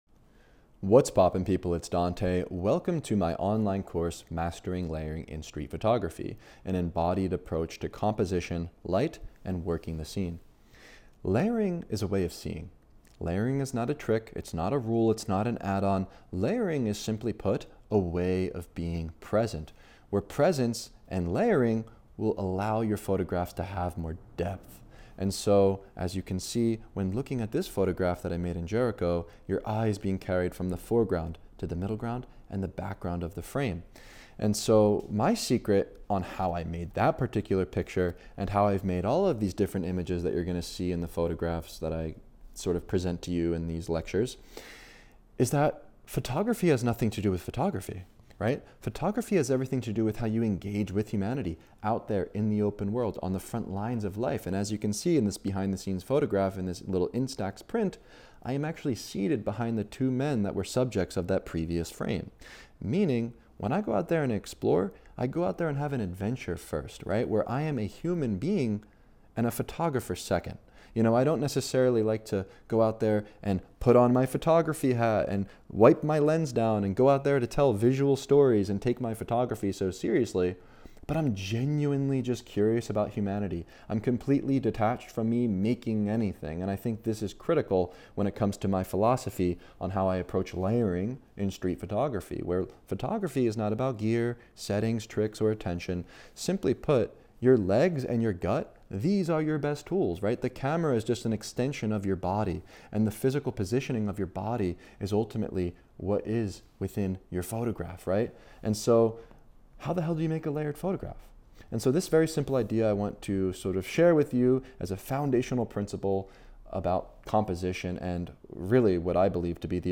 This opening lecture sets the foundation for the entire course.